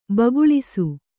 ♪ baguḷisu